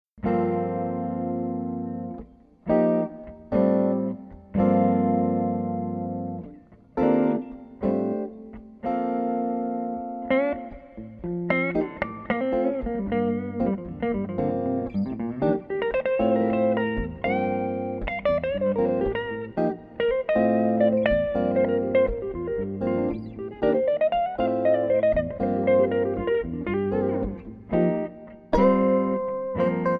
Guitar
Two Electric Guitar Entwine
Lyrical Jazz, Blues and Rock inflected Duets